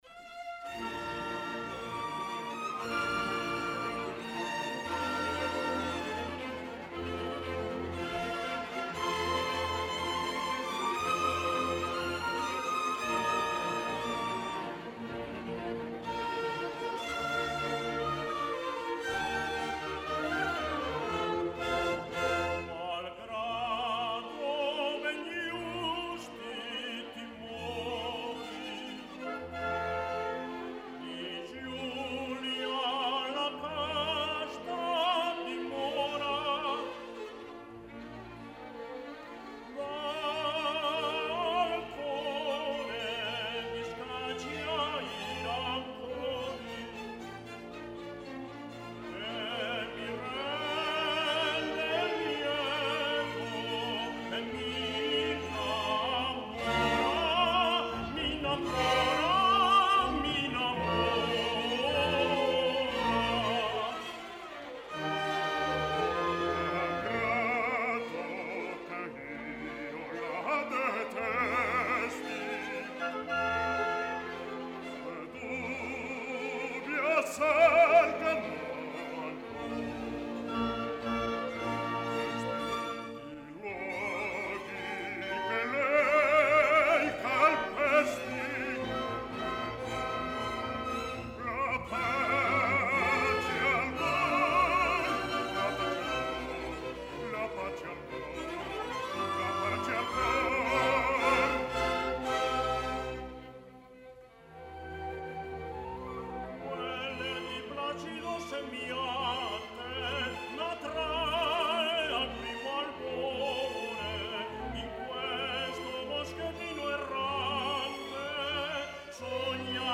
All’Aurora : duetto para tenor, bajo y orquesta (5 min., 28 seg.)
Concierto de clausura.
tenor
bajo